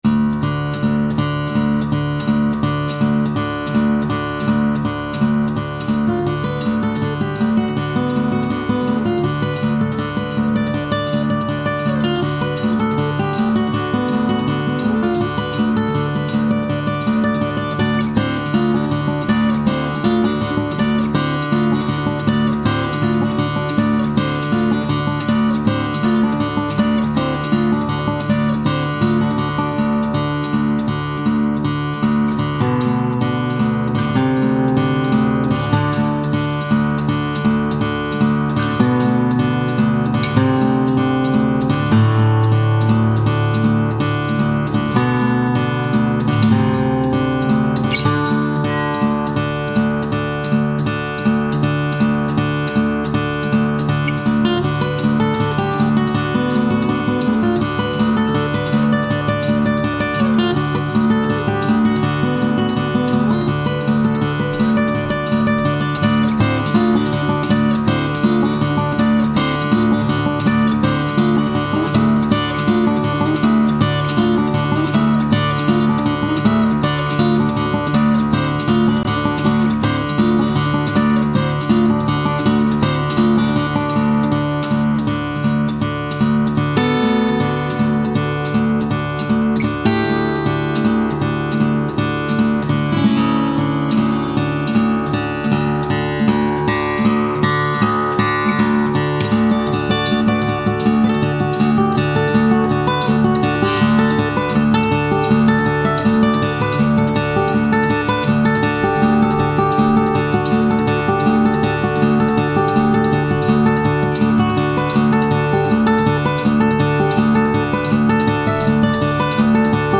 ovvero la chitarra acustica
straordinaria tecnica di fingerpicking